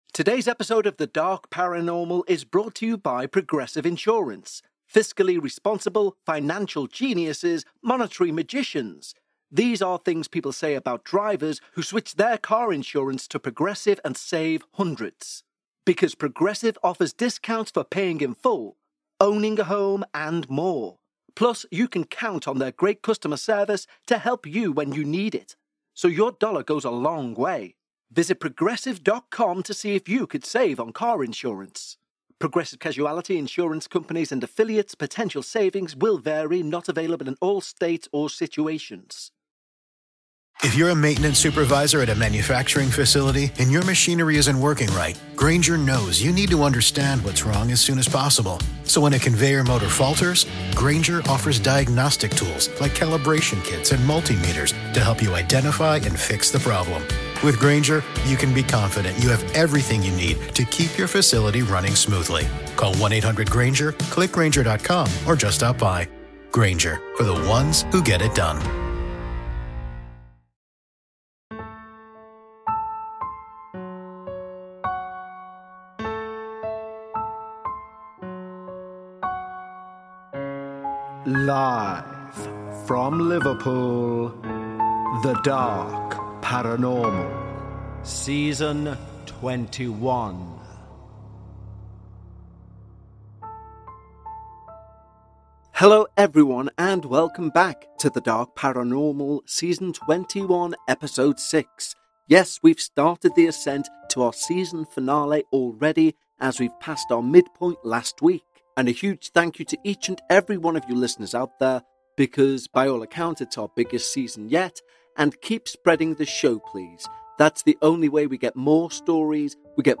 For our first step in to the second half of Season21, we hear from a woman in Canada who, after years of silence, finally opens up about the haunting experiences that shaped her childhood and early adulthood.